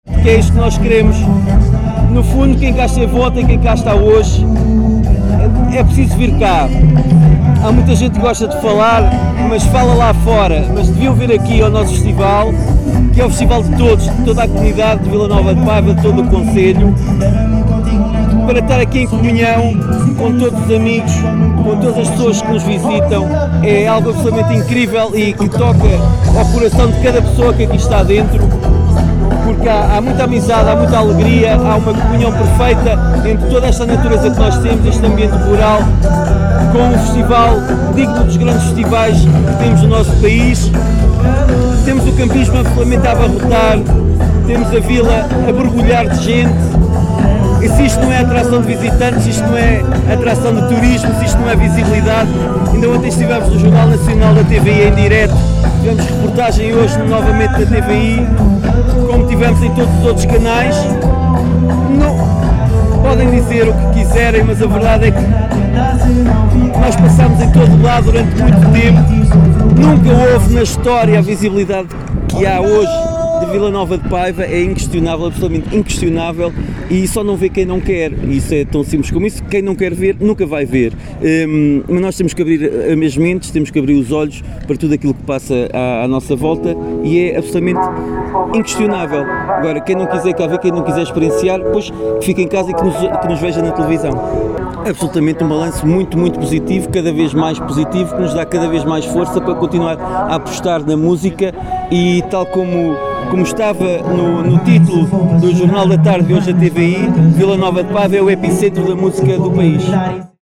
Paulo Marques, Presidente da Câmara Municipal de Vila Nova de Paiva, em declarações à Alive FM, disse que este é um festival de toda a comunidade local e de todos os visitantes, um balanço positivo.